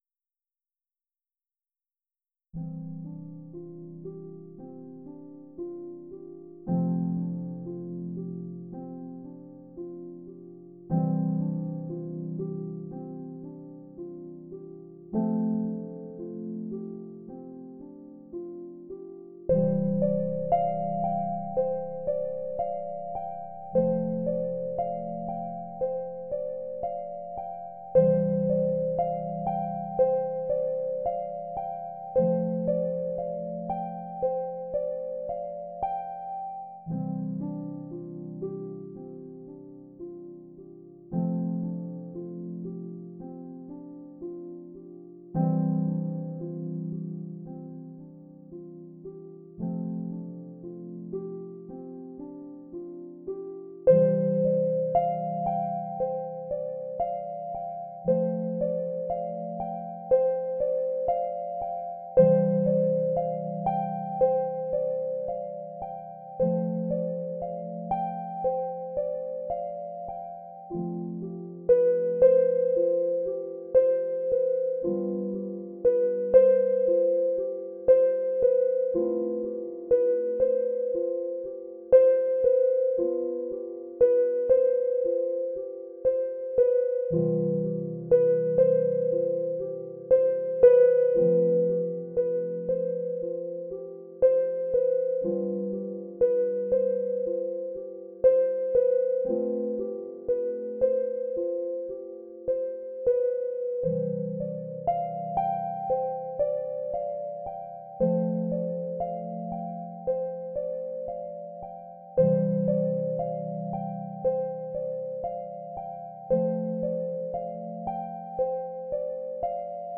普段はほとんど使わないのですが、この中に入っているエレクトリックピアノ風の音が結構いいです。
アンビエントはなんか柔らかい音の印象があるので、この音源はうまくハマりそうです。
たぶんハ長調。メロディを10分くらい考えてから１発録り。
シンプルですが、アンビエントにはシンプルな方が合います。